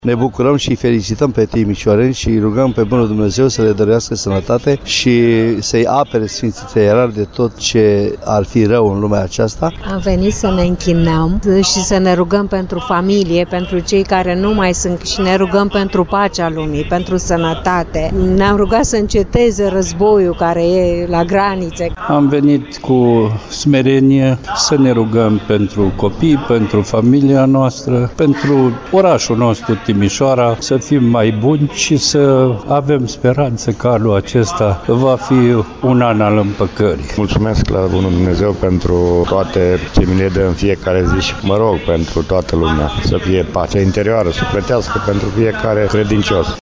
vox-hram-catedrala.mp3